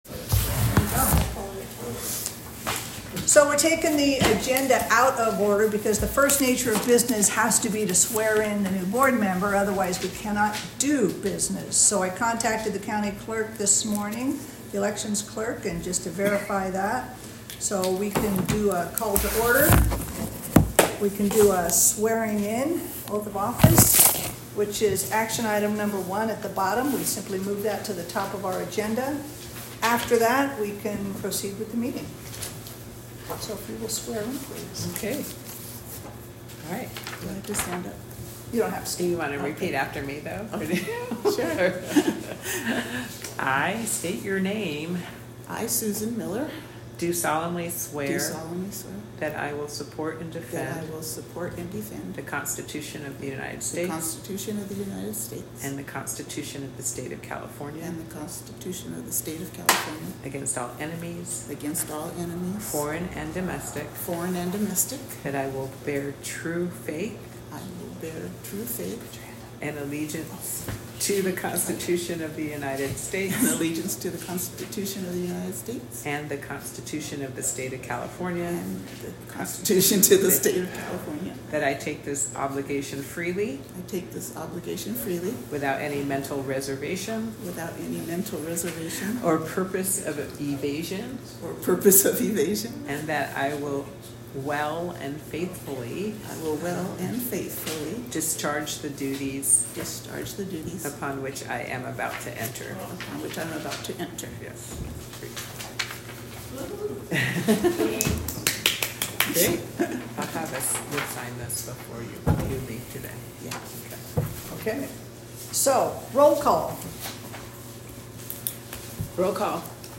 Board Meeting